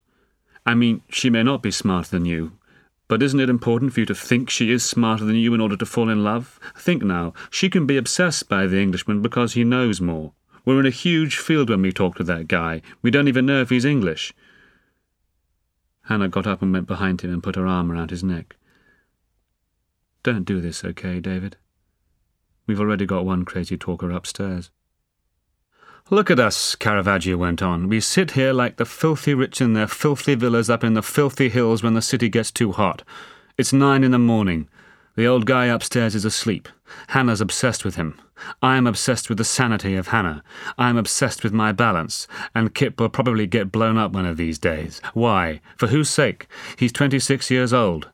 【有声英语文学名著】英国病人 60 听力文件下载—在线英语听力室